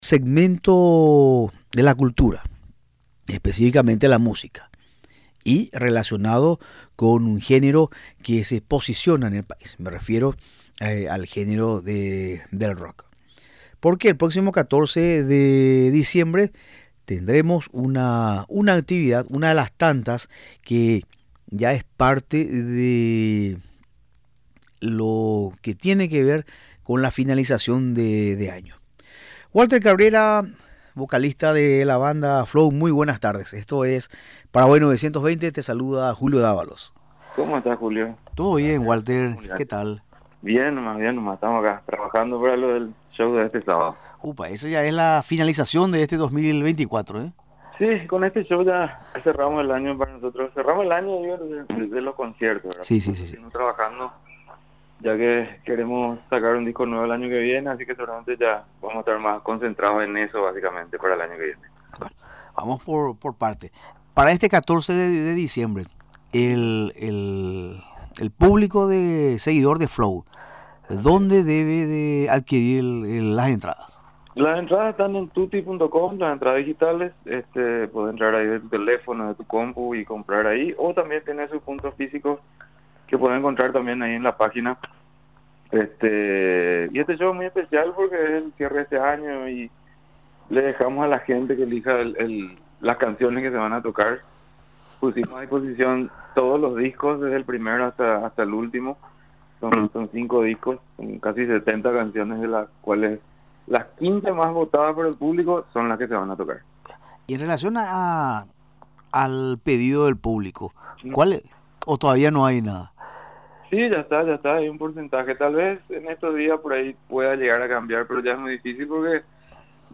en conversación en Radio Nacional del Paraguay